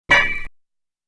Bing.wav